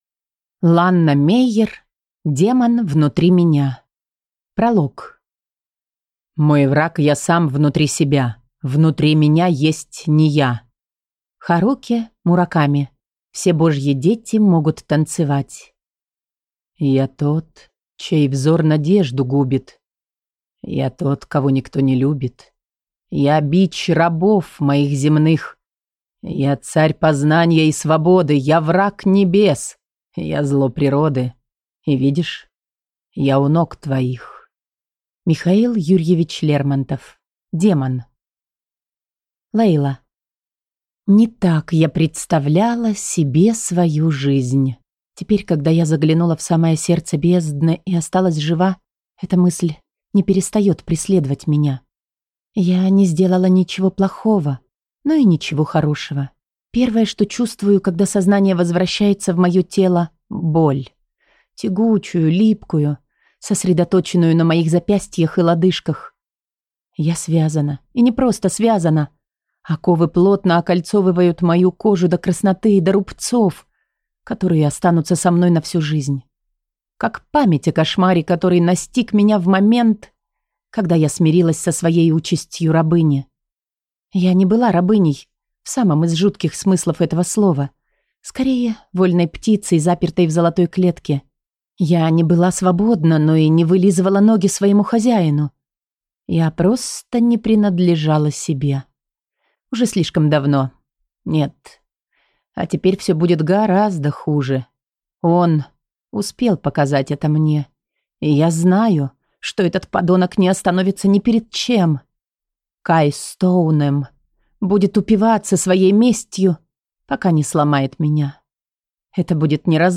Аудиокнига Демон внутри меня | Библиотека аудиокниг